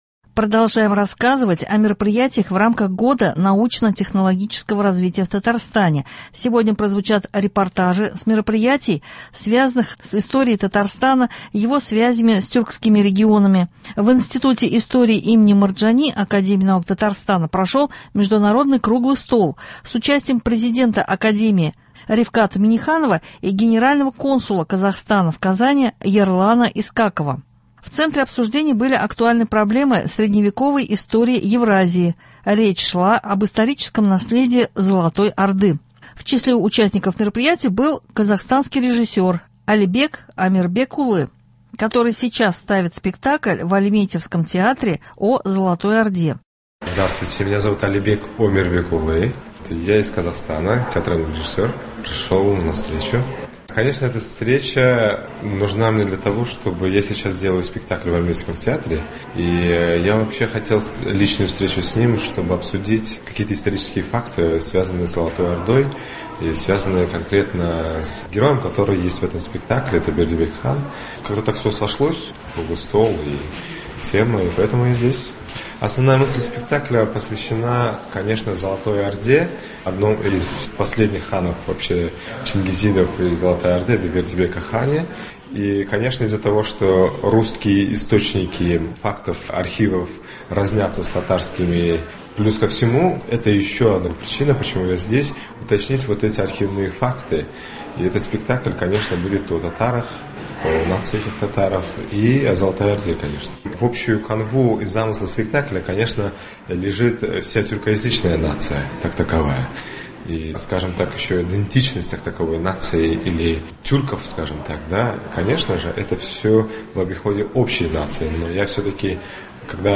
В эти дни в Татарстане - региональная неделя депутатов федерального уровня. В их числе – депутат Госдумы Айрат Фаррахов, с ним сейчас обсудим в студии